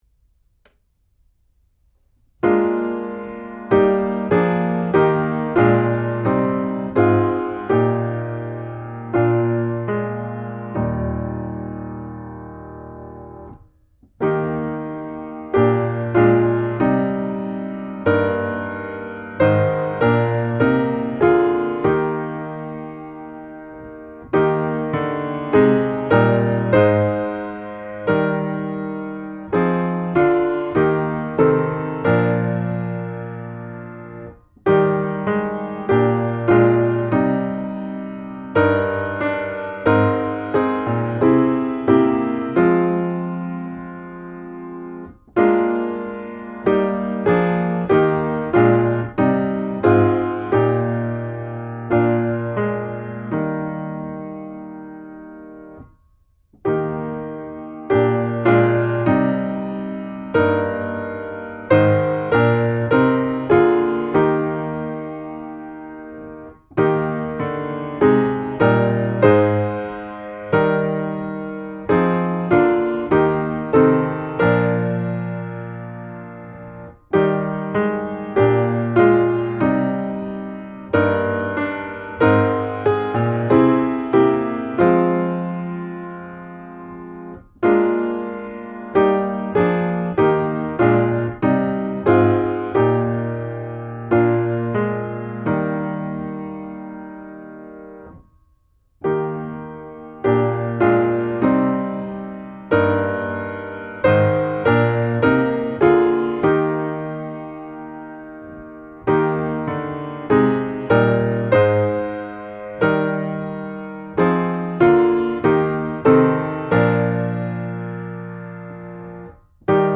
700-Abide_With_Me-piano.mp3